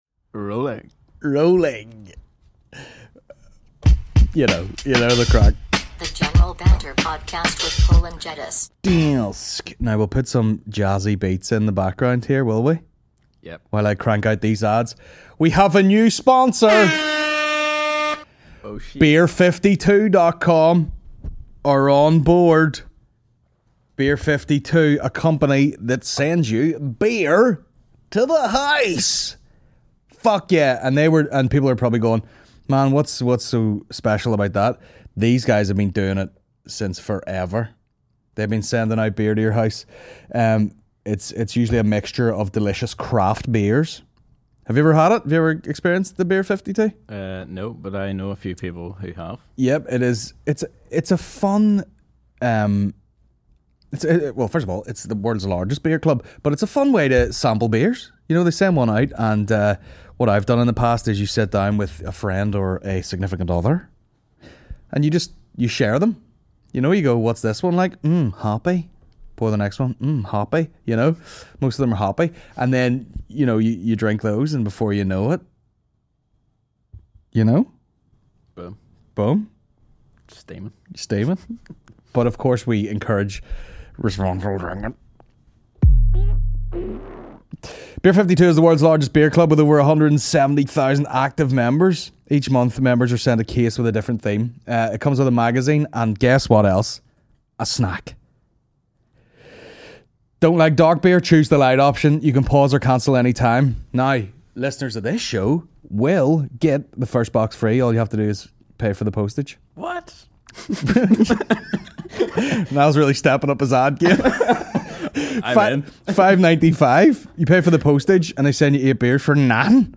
The General Banter Podcast is a Comedy podcast